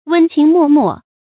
注音：ㄨㄣ ㄑㄧㄥˊ ㄇㄛˋ ㄇㄛˋ
溫情脈脈的讀法